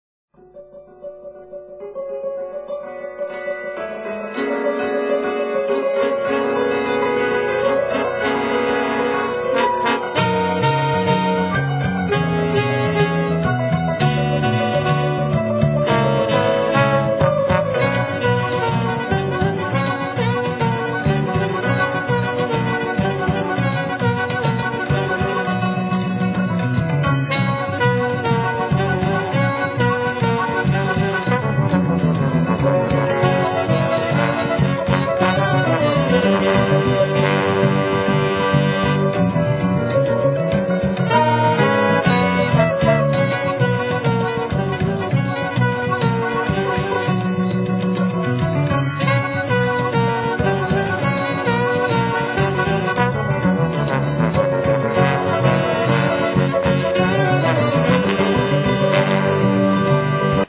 Cimbaloom, Vocals, Drums
Tenor saxophone
Percussions
Flute
Trumpet
Trombone
Bass
Tablas
Alt saxophone